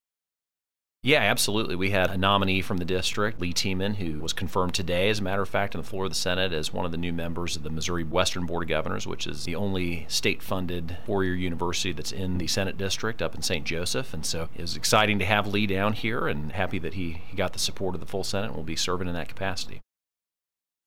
3. Senator Luetkemeyer also says his first committee hearing is coming next week.